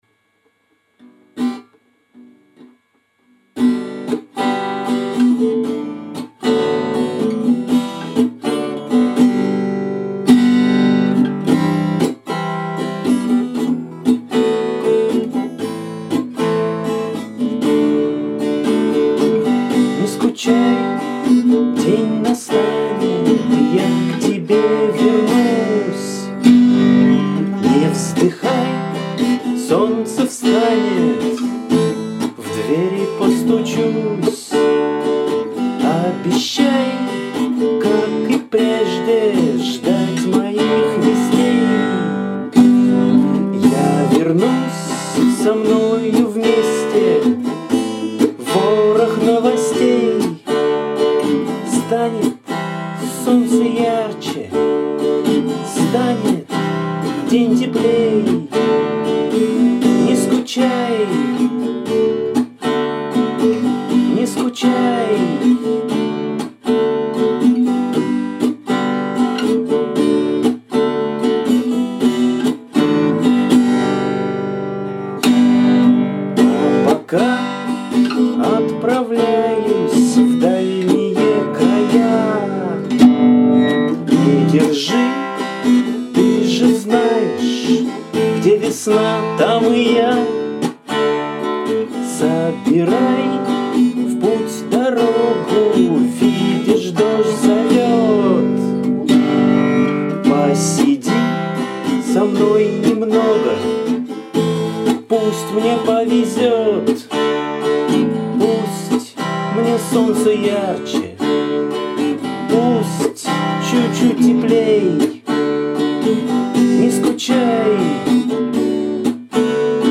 какое-то юное, беззаботное вышло исполнение.
Мне нравится открытость и некая простота исполнения.